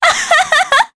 Juno-Vox_Happy3_jp.wav